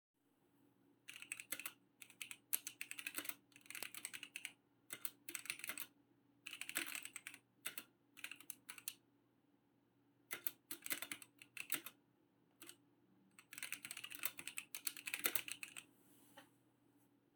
Összesen ötféle kapcsoló közül választhatunk, a teszt alanya a sárgával érkezett, amely lineáris, csendes, nem kattog és 50 gramm erővel nyomható le.
A hangja
Közepesen hangos, nem kattog (a felvétel közelről készült).